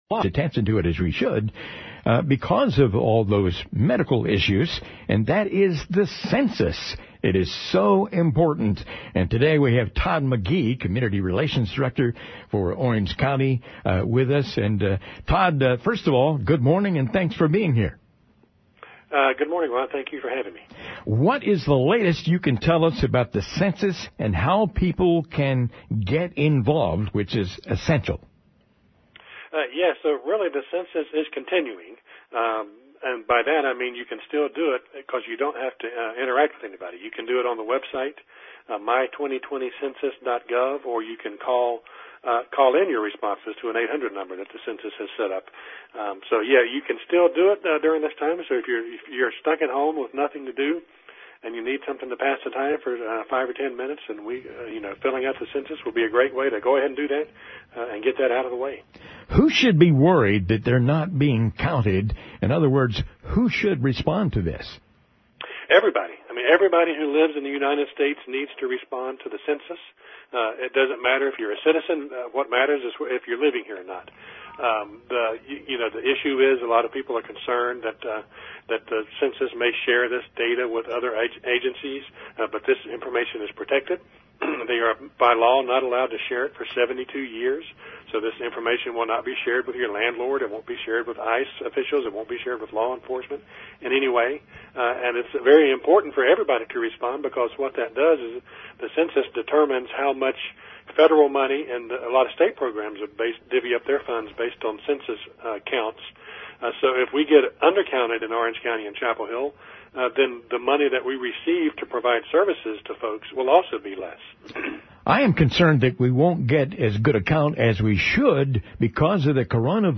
a conversation about how the coronavirus is impacting local Be Counted sites in Orange County, as well as ways to respond to the Census during quarantine.